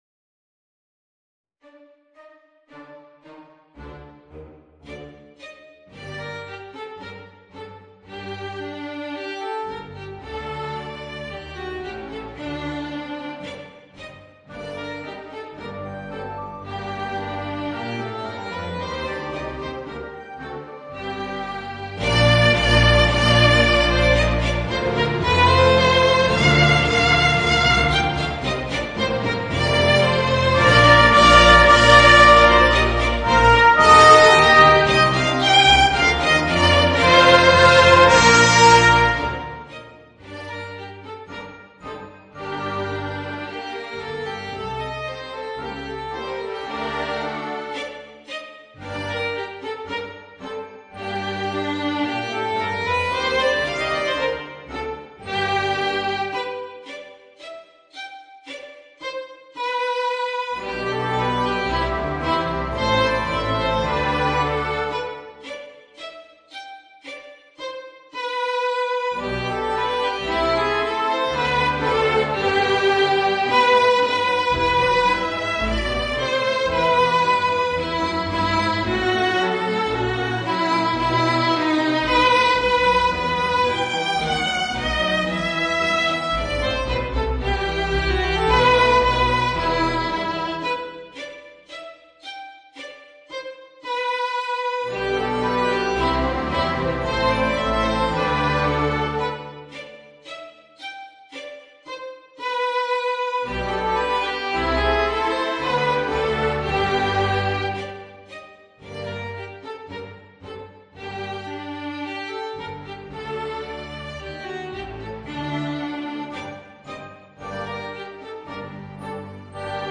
Voicing: Viola and Orchestra